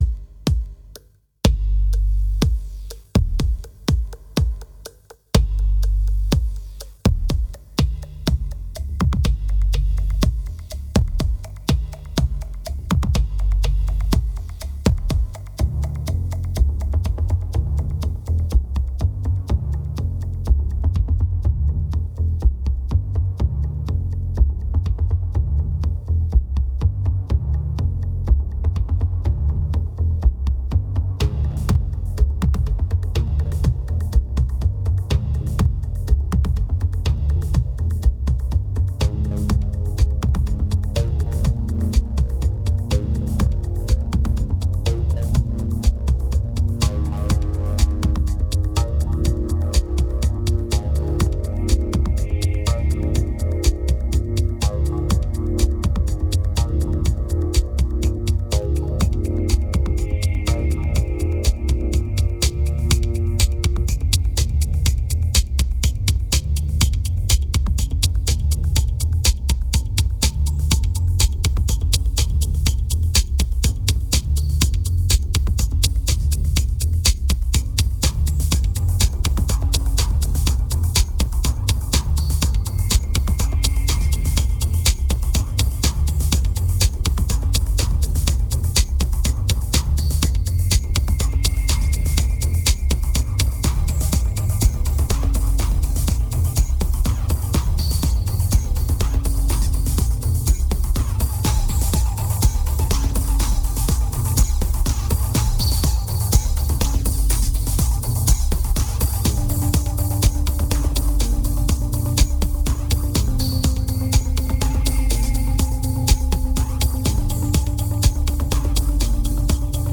2308📈 - -6%🤔 - 123BPM🔊 - 2010-03-09📅 - -199🌟